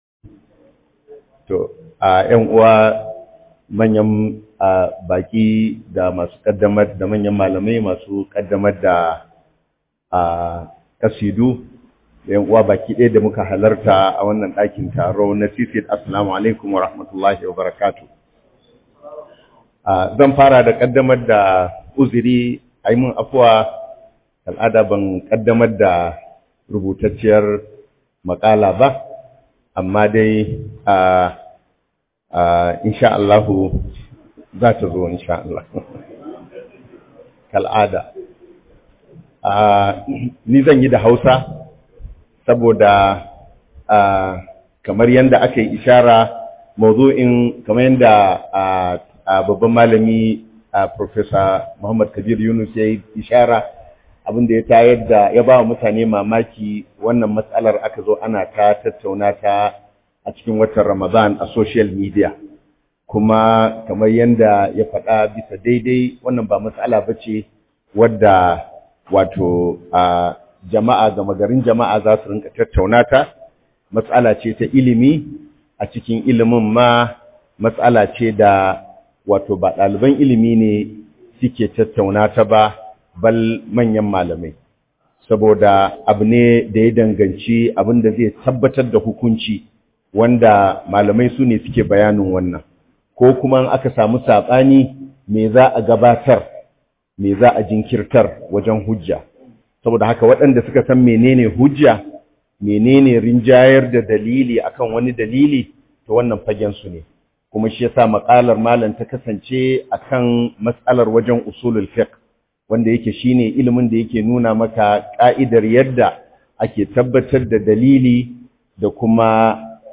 DANGANTAKA TSAKANIN HADITH DA ILIMIN USULUL FIQHU - MUHADARA